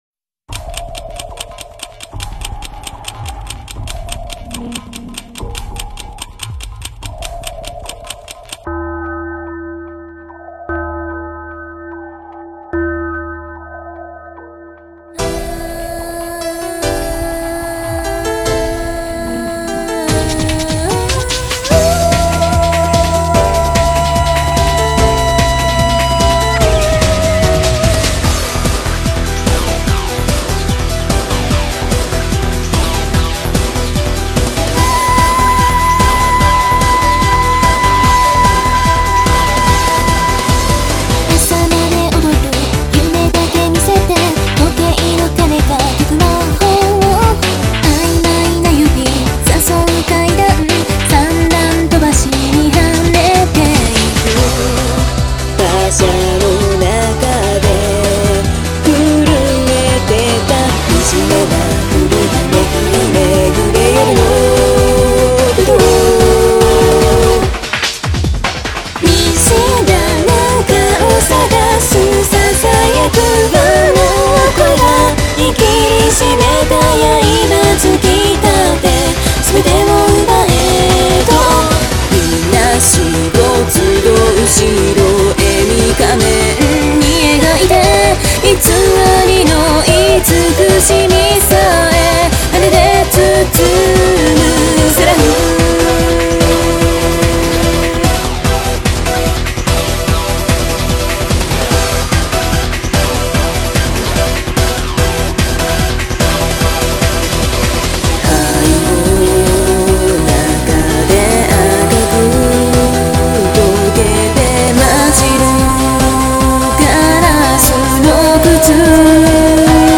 でもniconicoやつべにあげる勇気はないので、こっそりブログで歌ってみました。(ヘッタクソなんで完全に自己満足です)